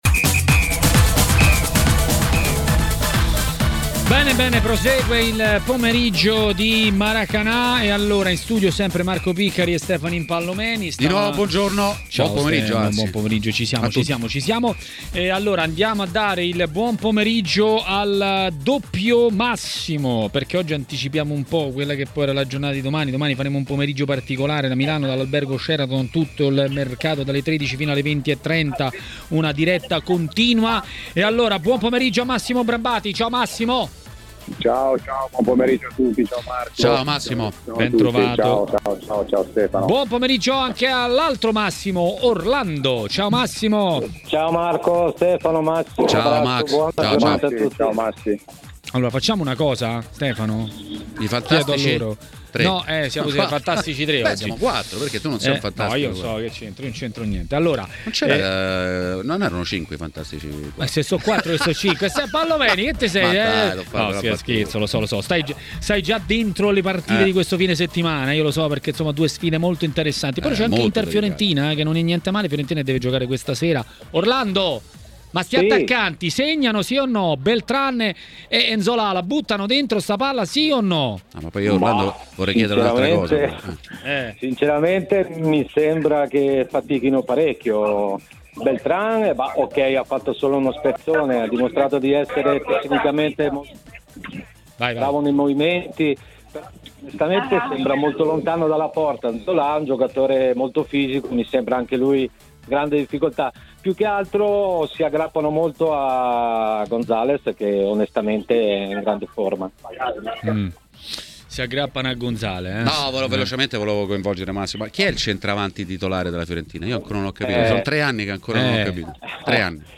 è intervenuto ai microfoni di TMW Radio, durante la trasmissione Maracanã, per dare i voti al calciomercato delle italiane: